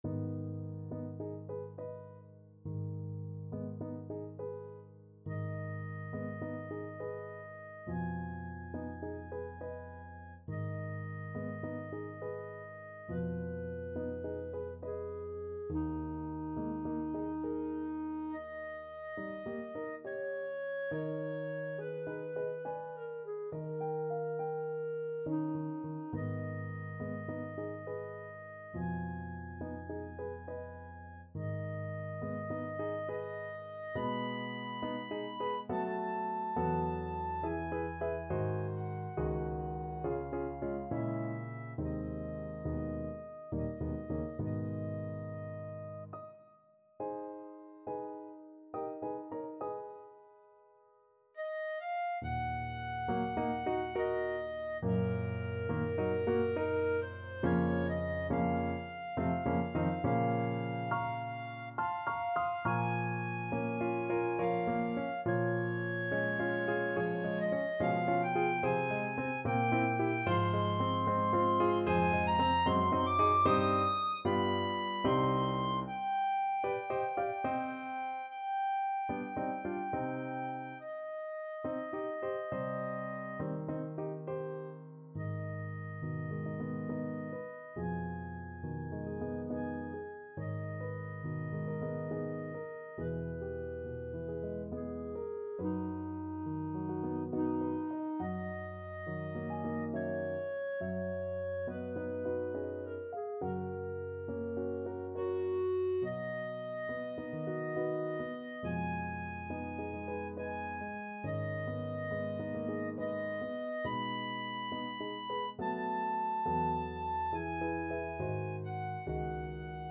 Clarinet version
Eb5-Eb7
3/8 (View more 3/8 Music)
Andante =69
Classical (View more Classical Clarinet Music)